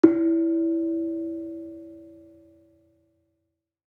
Kenong-resonant-E3-f.wav